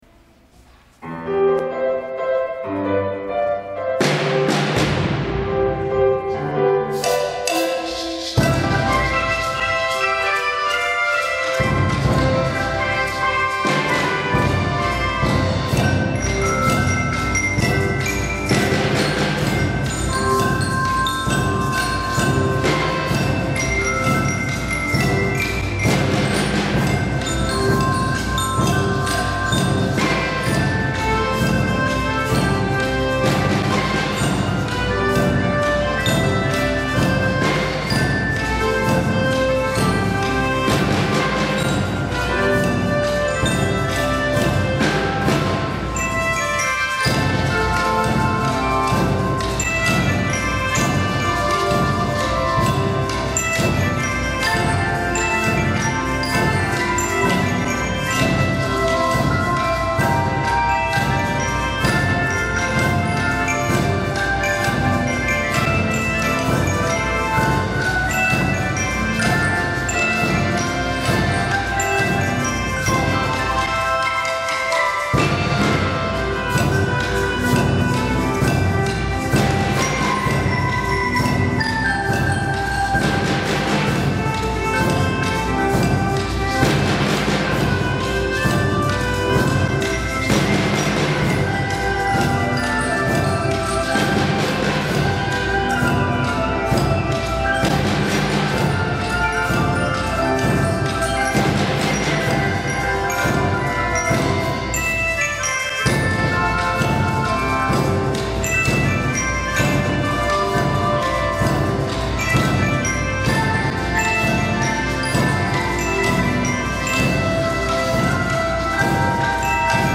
聞いている人も、演奏している子どもたちも自然と笑顔があふれ、スマイルいっぱいのあたたかい空気に包まれました。